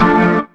B3 DMIN 1.wav